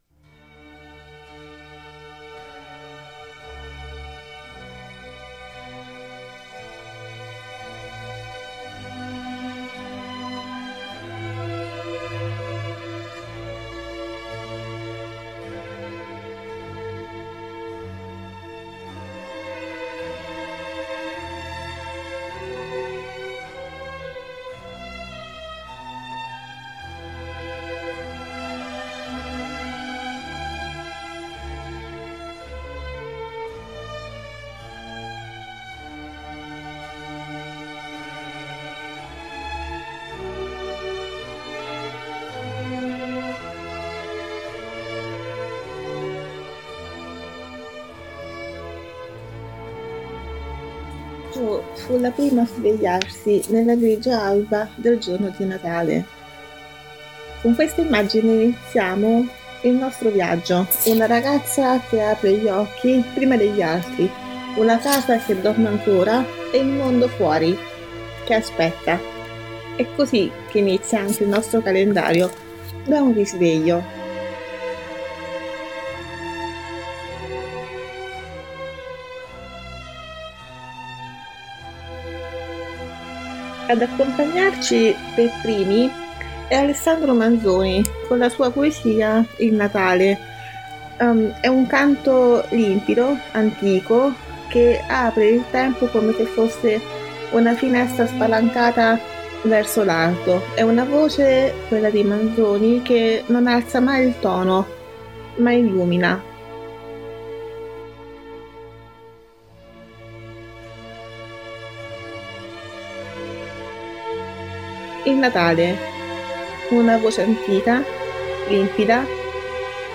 Ad accompagnare questo inizio di viaggio nel Natale letterario, una colonna sonora senza tempo: la bellezza meditativa e solenne di J.S. Bach con la sua Aria sulla IV Corda.